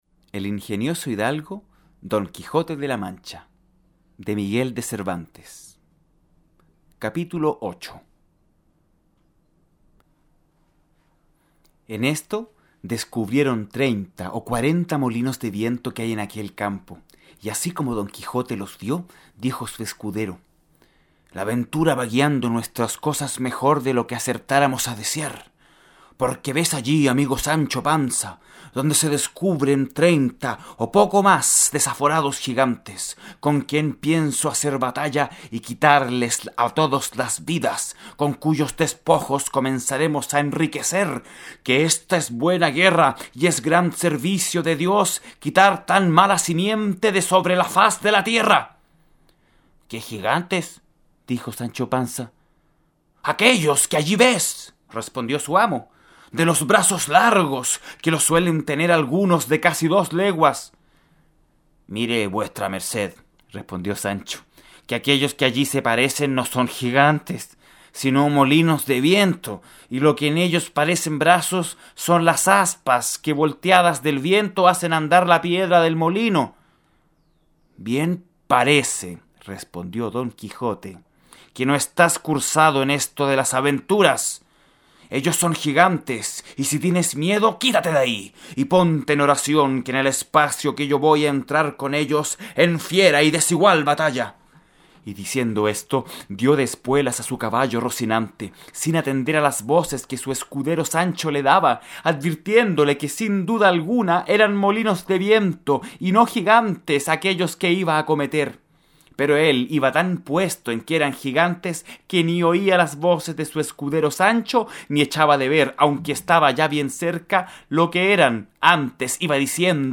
Audiolibro del Episodio de los molinos de viento de "El Quijote de la Mancha", correspondiente al capítulo 8.